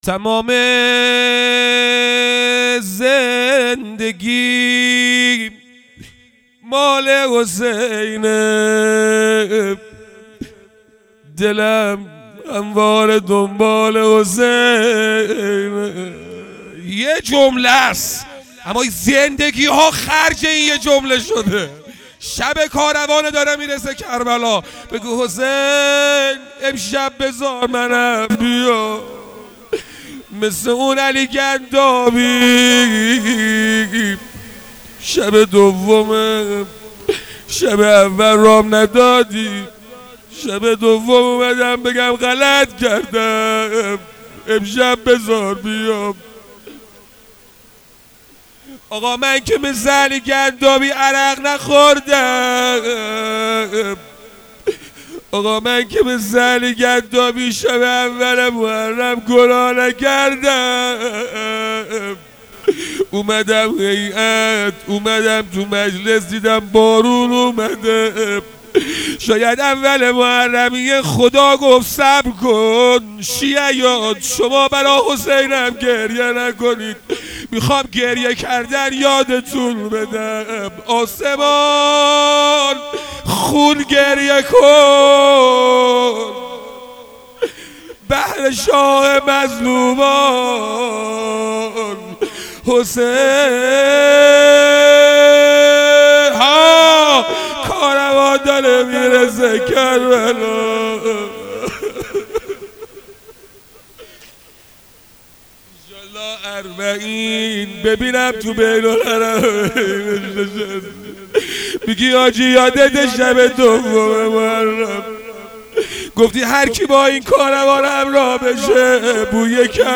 شب دوم محرم الحرام1401-هیئت رایةالزهرا سلام الله علیها یزد